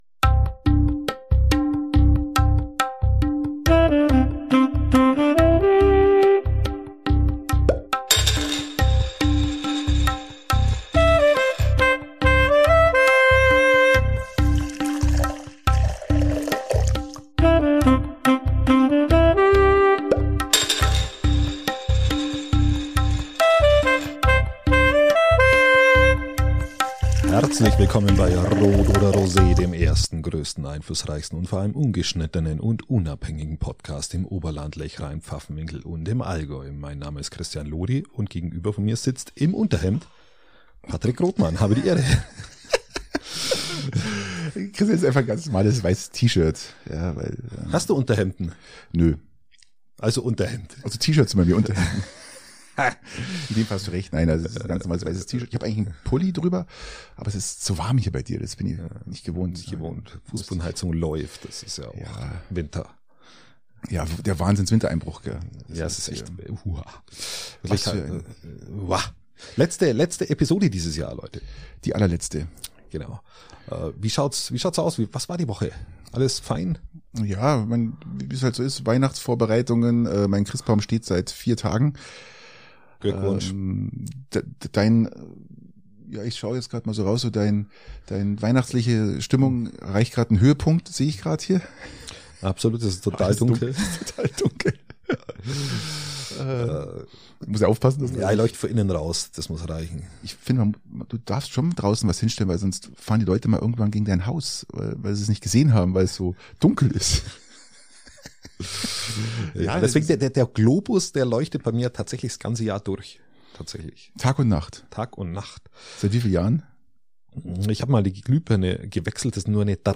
Ungeschnittenen und unabhängig!